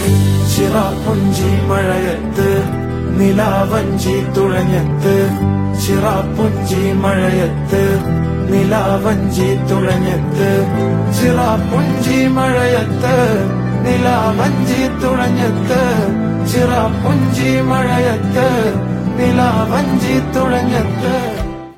Ringtone File
a poetic, emotional indie song